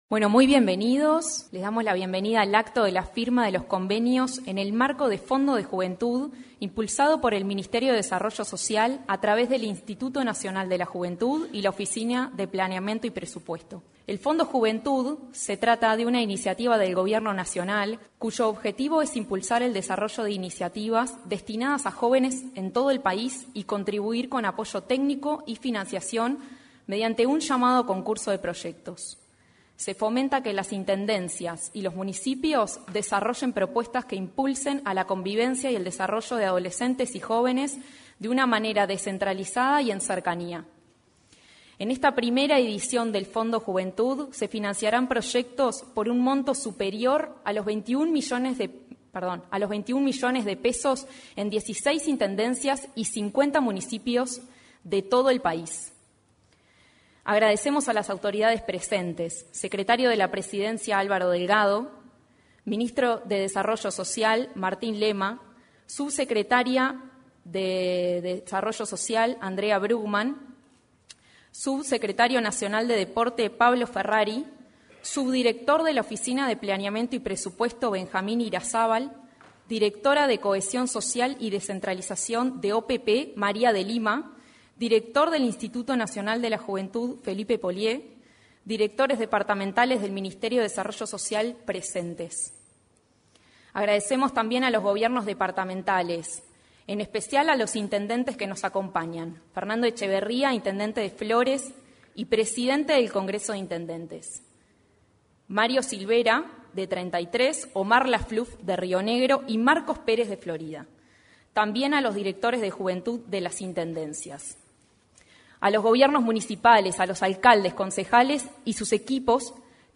Este miércoles 21, en el auditorio de la Torre Ejecutiva anexa, la Oficina de Planeamiento y Presupuesto (OPP), el Ministerio de Desarrollo Social